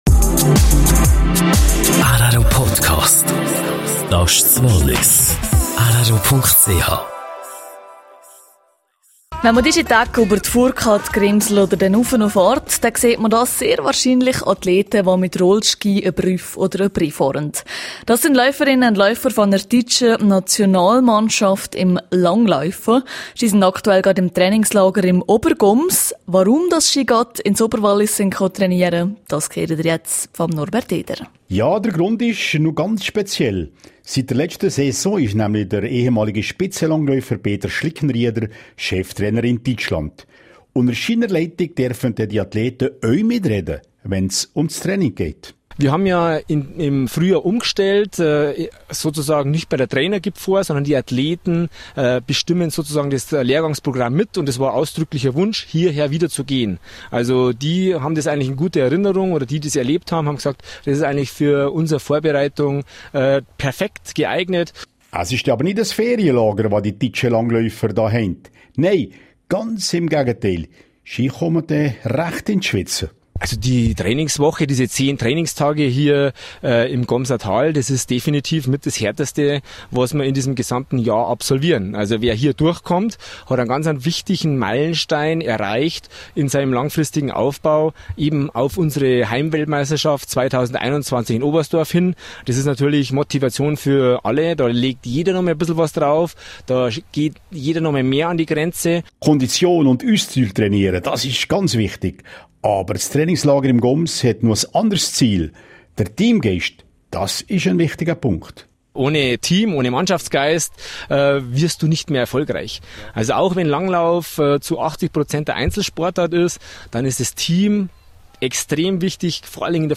Interview mit Cheftrainer Peter Schlickenrieder.